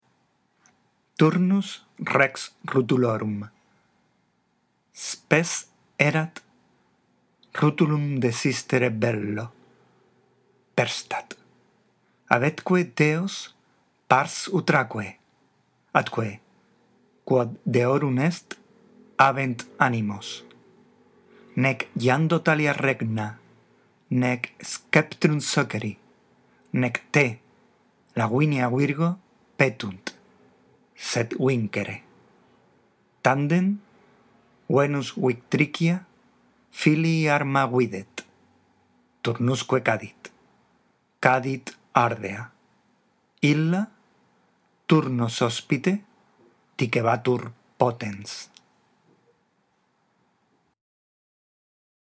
La audición de este archivo te ayudará en la práctica de la lectura